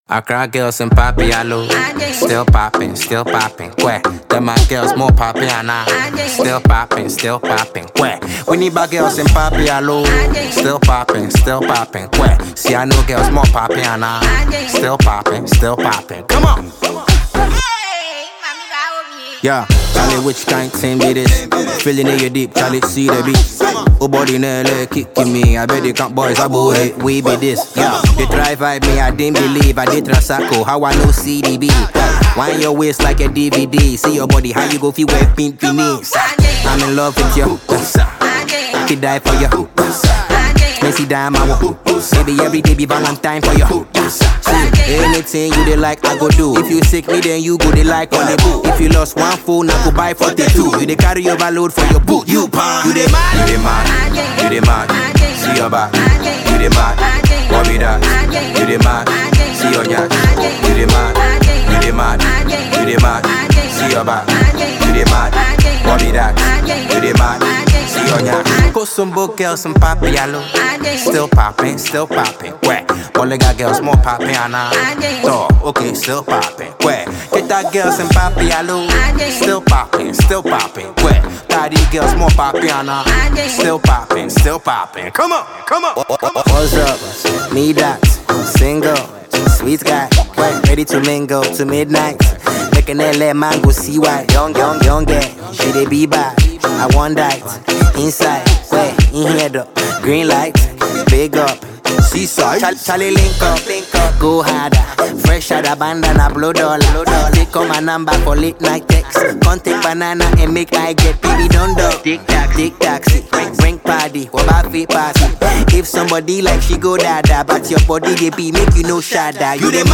Ghana Music
Ghanaian highly-rated rapper and singer